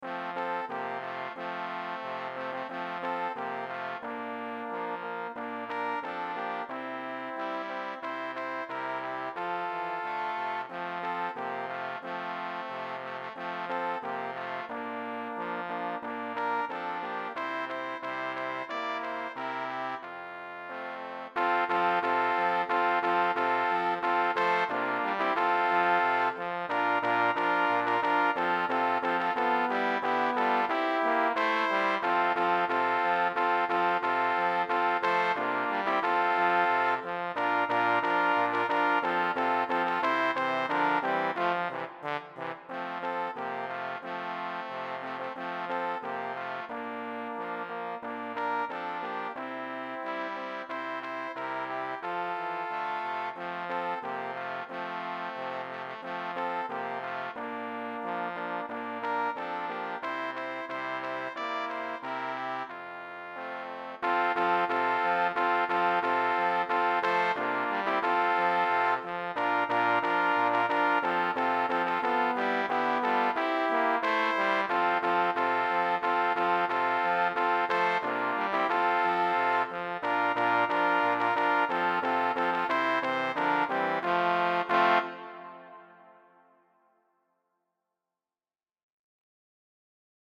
BRASS QUARTET
QUARTETTO • BASE MP3
Trbn. 1
Trbn. 2